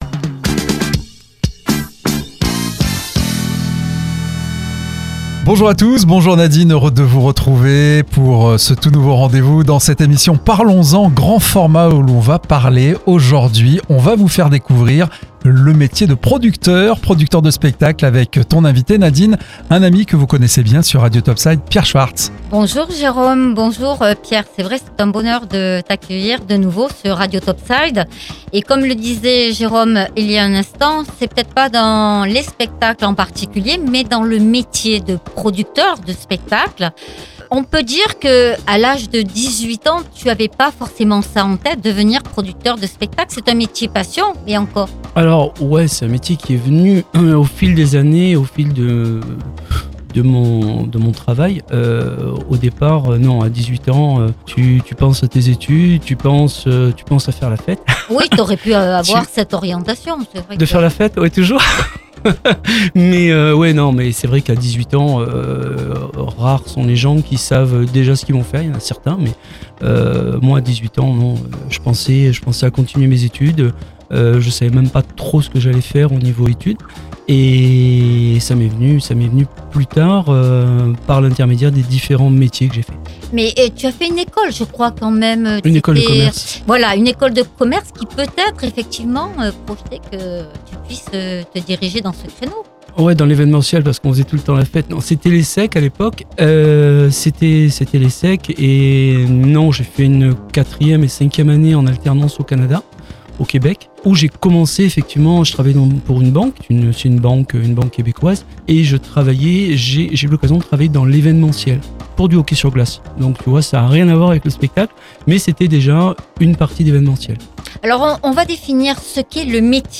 dans les studios de Radio Top Side.